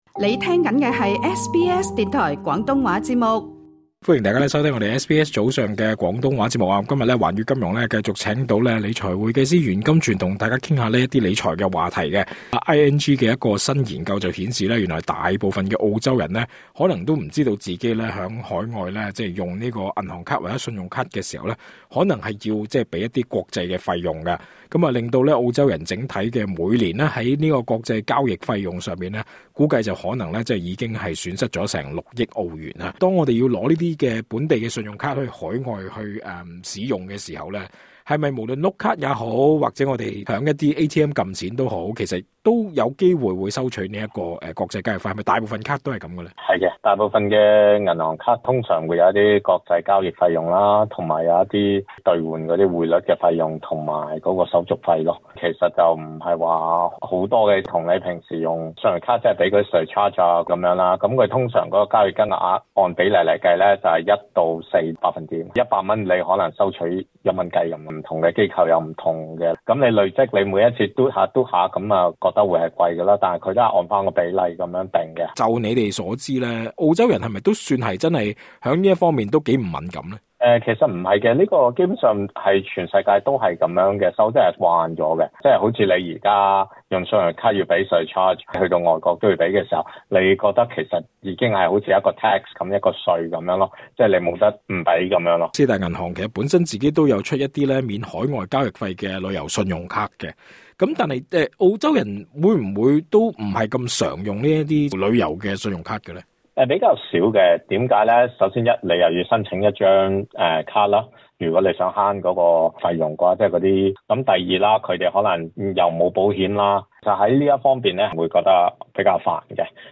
足本訪問: LISTEN TO 【不知情？